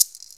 LATIN SOFT.wav